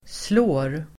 Uttal: [slå:r]